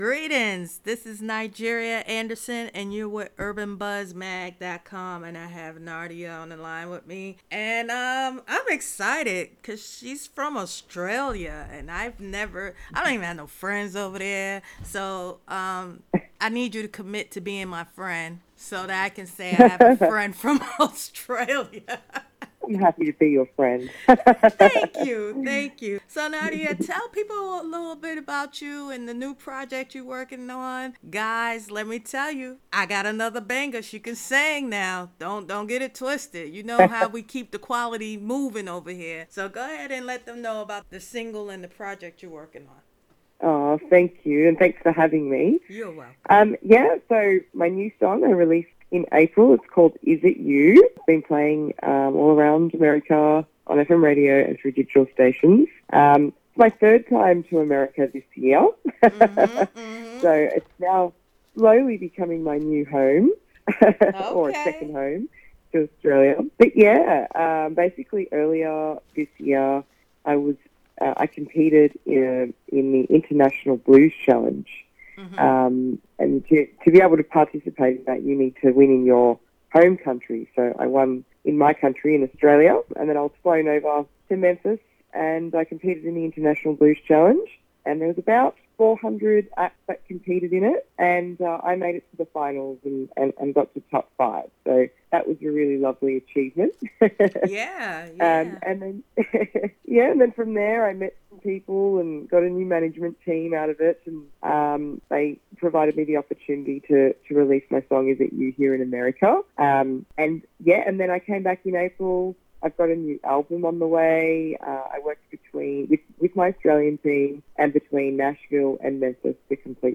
This interview is for everyone who thought they had it all figured out and then, after one moment in time, everything changes.